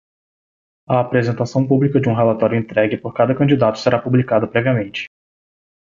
Pronunciado como (IPA)
/ˌpɾɛ.vi.aˈmẽ.t͡ʃi/